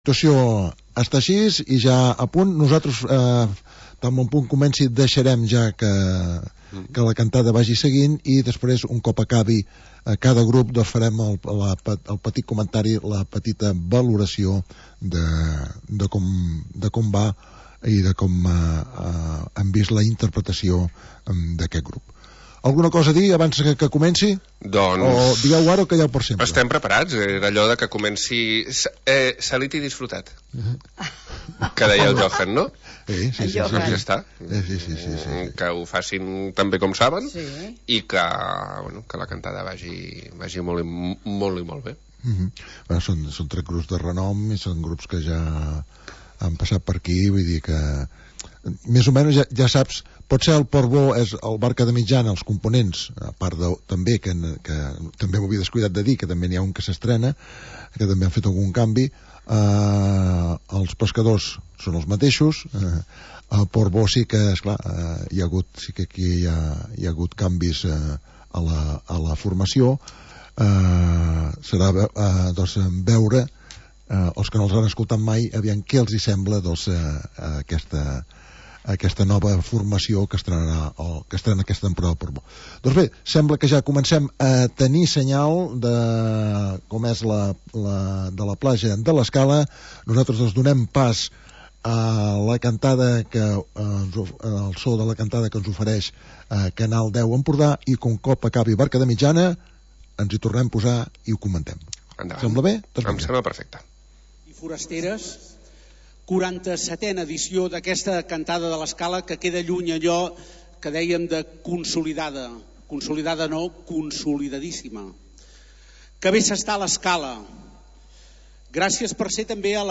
Transmissió Cantada Havaneres